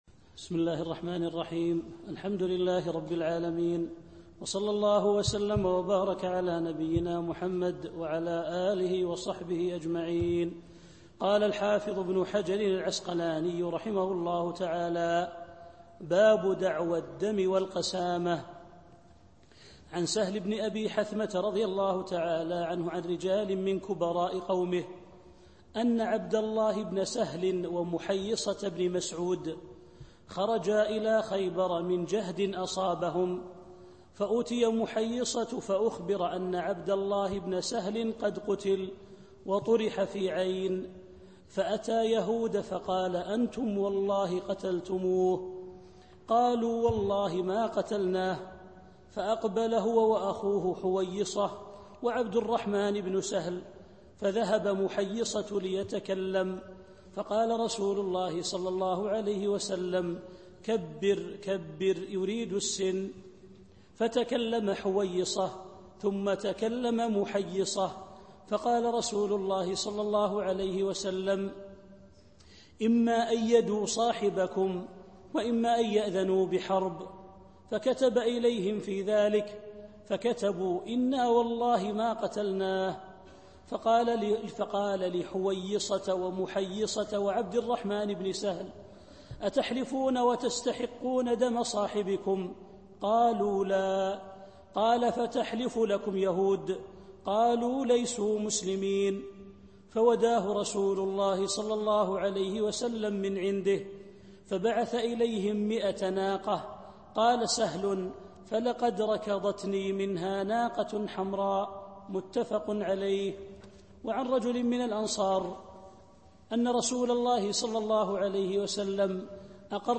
الدرس الرابع من دروس شرح بلوغ المرام كتاب الجنايات للشيخ عبد الكريم الخضير